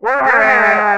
YELL      -L.wav